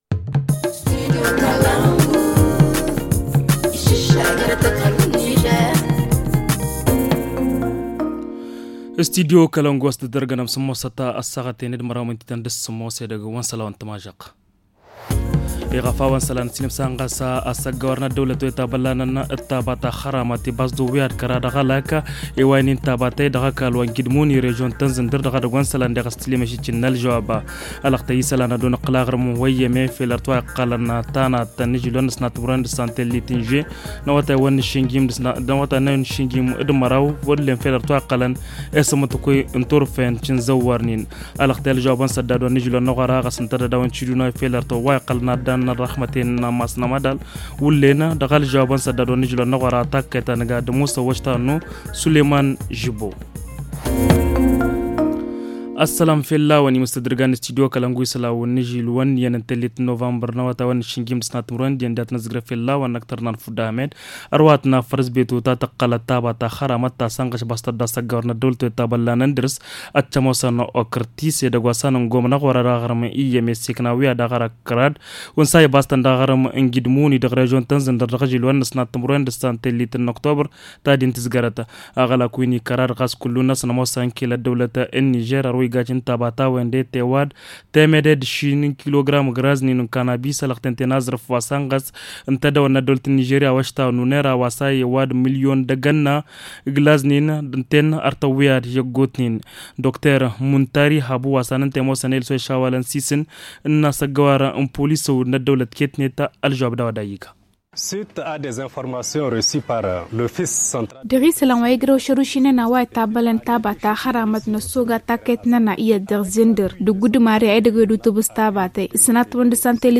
Le journal du 01 novembre 2021 - Studio Kalangou - Au rythme du Niger